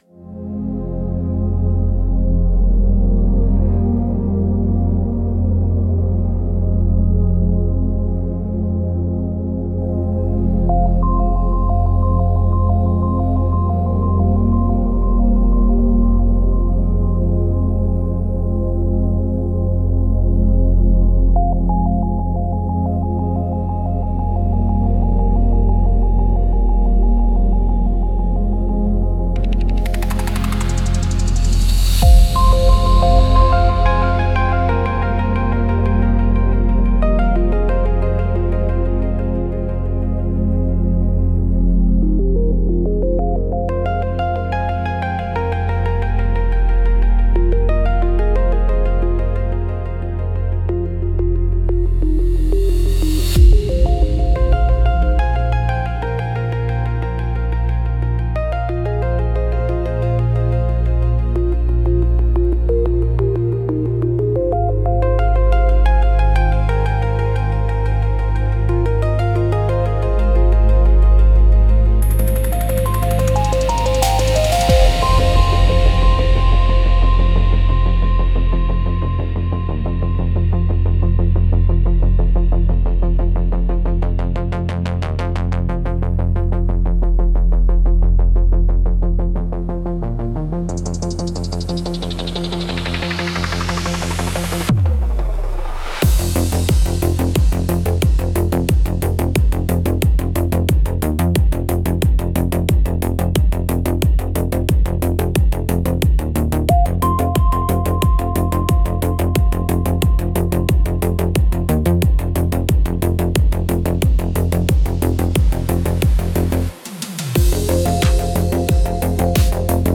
Space-Ambient-Album